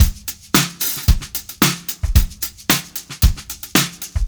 • 112 Bpm Drum Groove C Key.wav
Free drum loop - kick tuned to the C note. Loudest frequency: 3962Hz
112-bpm-drum-groove-c-key-kqa.wav